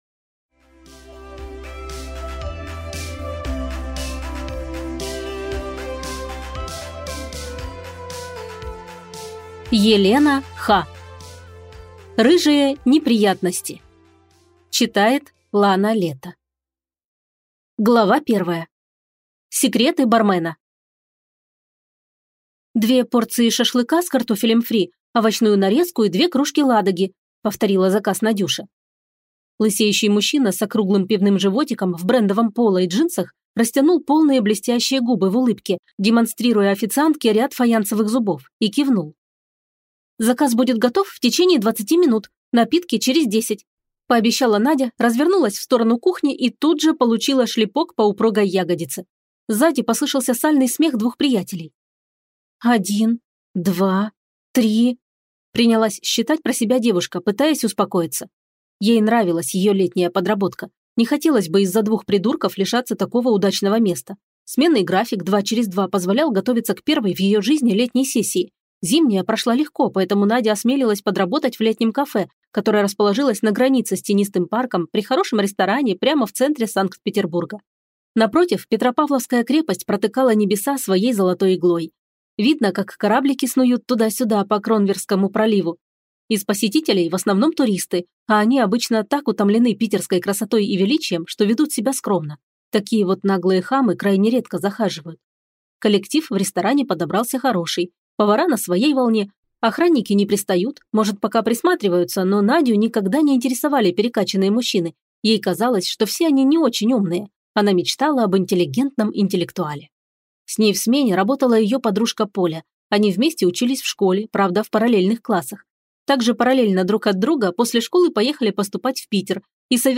Аудиокнига Рыжие неприятности | Библиотека аудиокниг